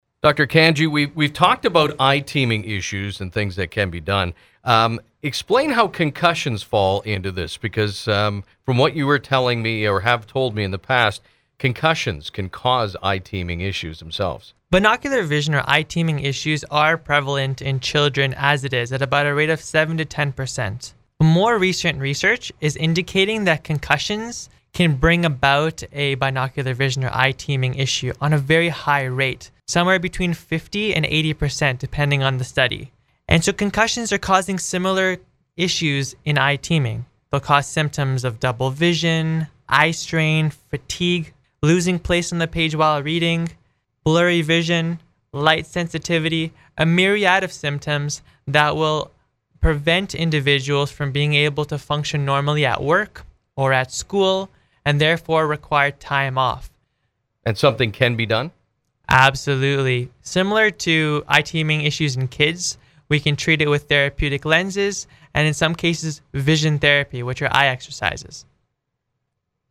short interviews